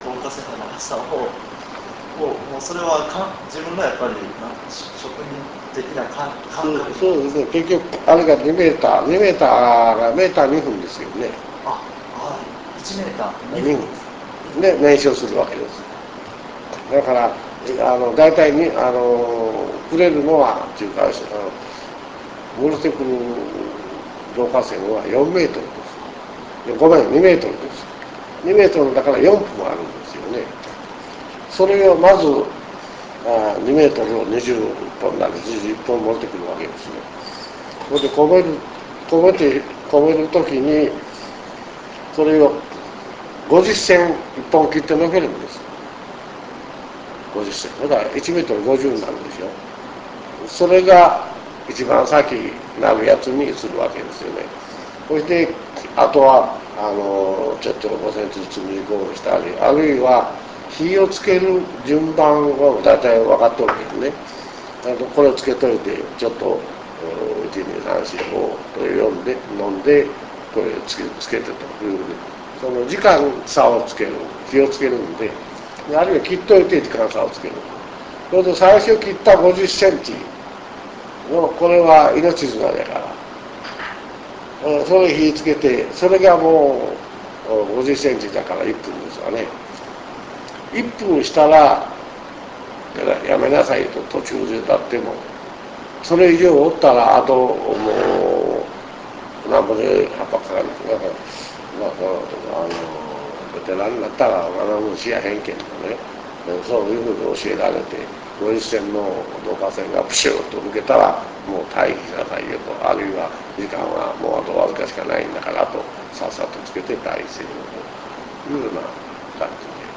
インタビュー ビデオをご覧いただくには、RealPlayerソフトが必要です。